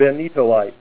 Help on Name Pronunciation: Name Pronunciation: Benitoite + Pronunciation